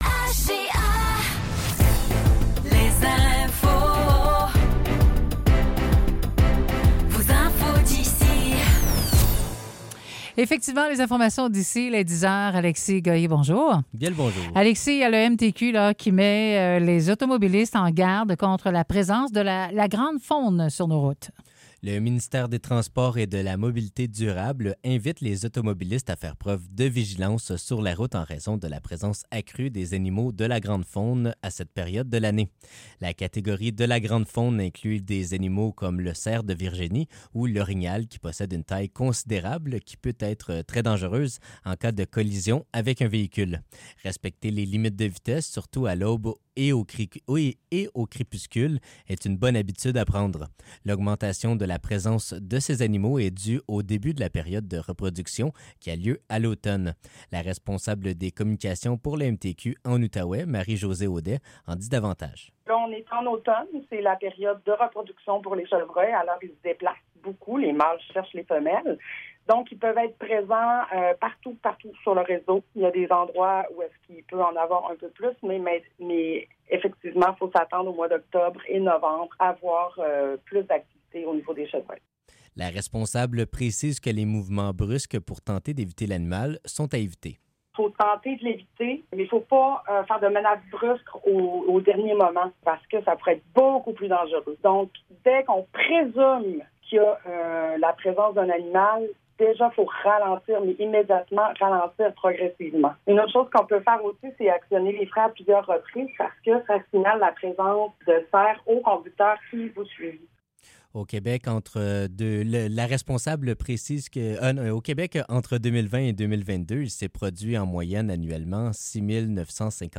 Nouvelles locales - 28 octobre 2024 - 10 h